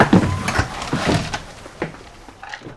fueltank.wav